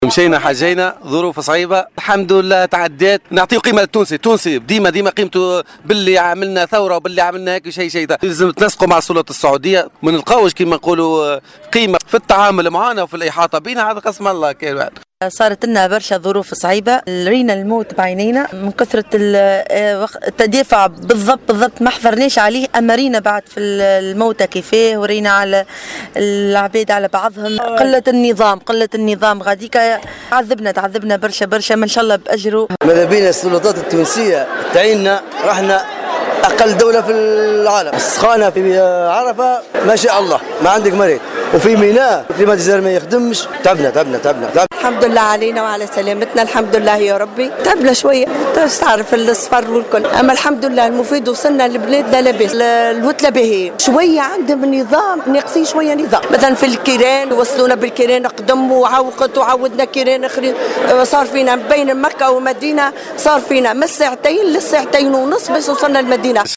وقد تحدّث الحجيج عن الظروف الصعبة التي مروا بها في البقاع المقدسة وتدني خدمات النقل والسكن.